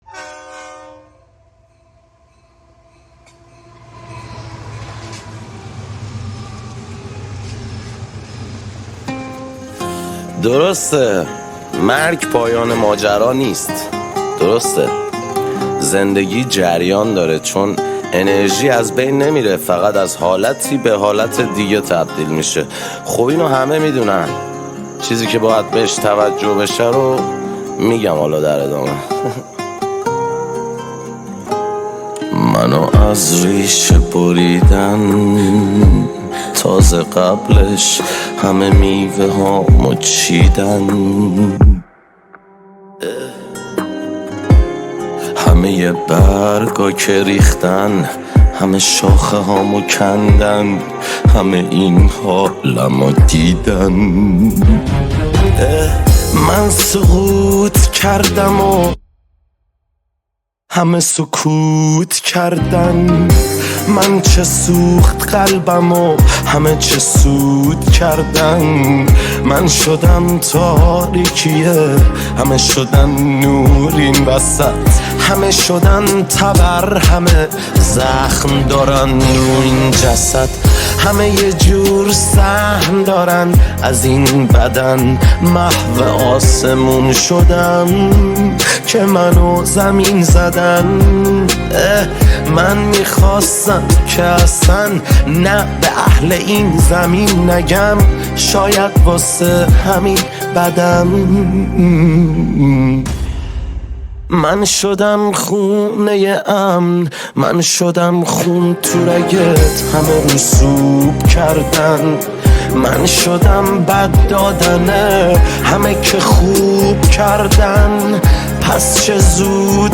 تک آهنگ
آراَندبی